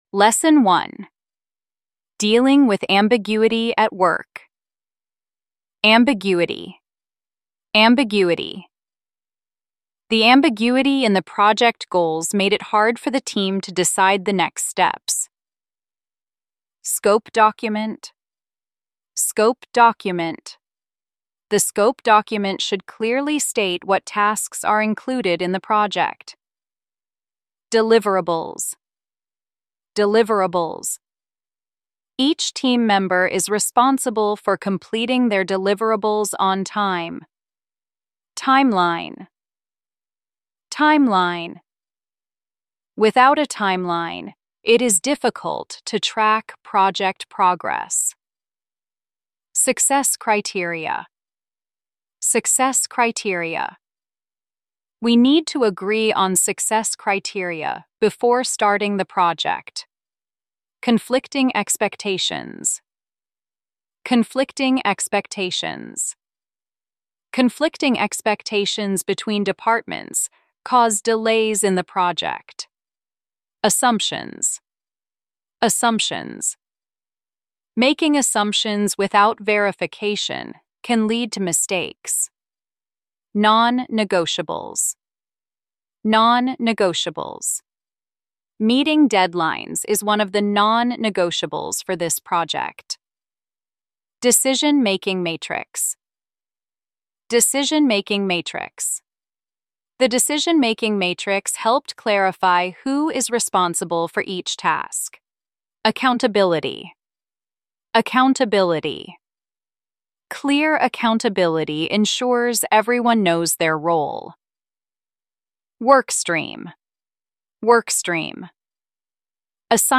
Nghe thử sách: Giọng tự nhiên